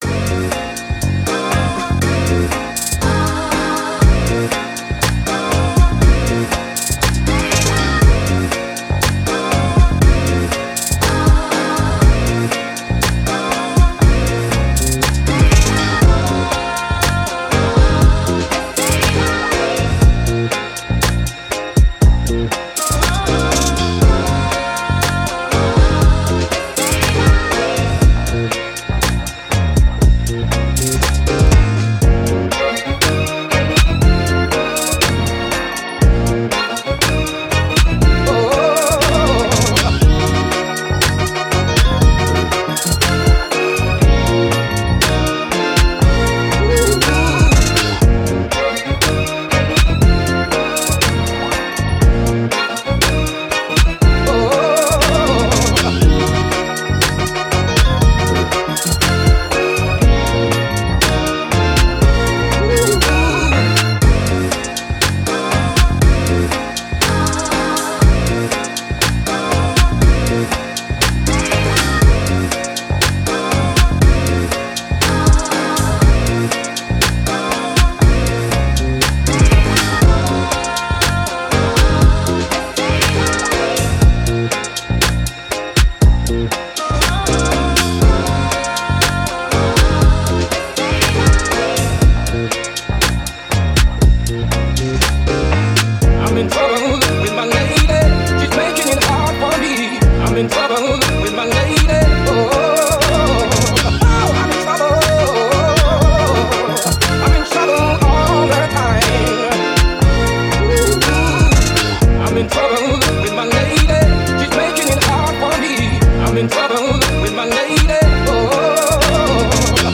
Hip Hop, Trap, Upbeat, Action